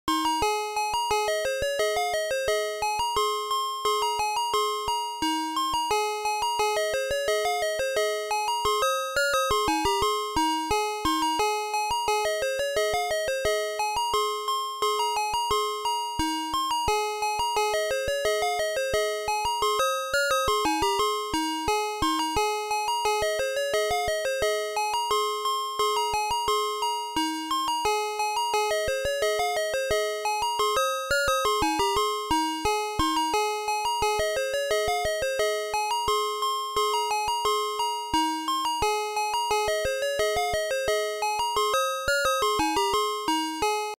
دانلود آهنگ ماشین بستنی فروشی 1 از افکت صوتی حمل و نقل
دانلود صدای ماشین بستنی فروشی 1 از ساعد نیوز با لینک مستقیم و کیفیت بالا
جلوه های صوتی